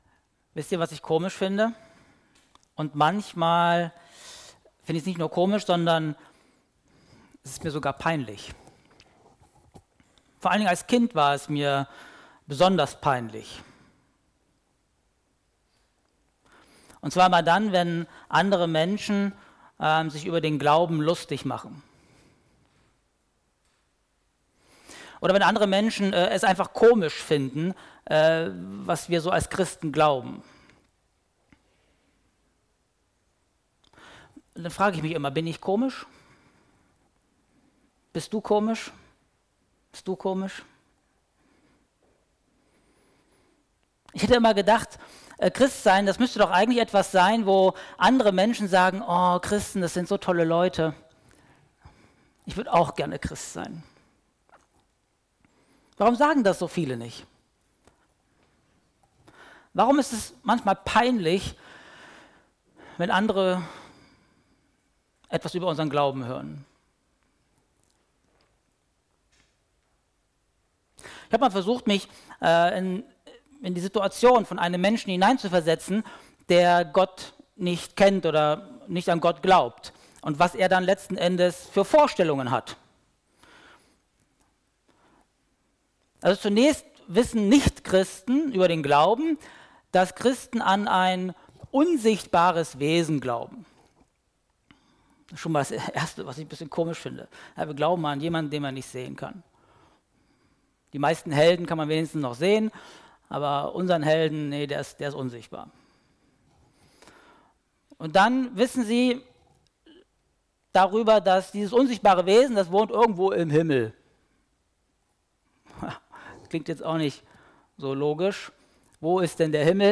2024 in Gottesdienst Keine Kommentare 217 LISTEN